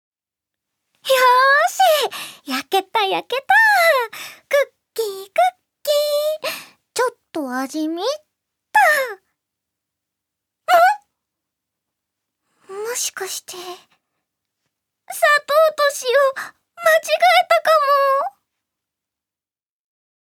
セリフ２